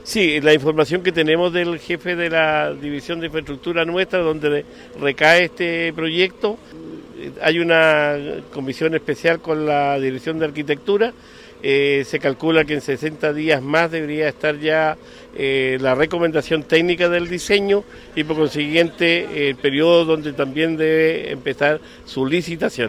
La Radio le consultó al gobernador regional, Luis Cuvertino, por plazos para concretar el proyecto, señalando que esperan obtener la recomendación técnica del Museo del Vapor en 60 días. Una vez resuelto ese trámite, se remitirán los antecedentes al MOP para publicar la licitación del diseño.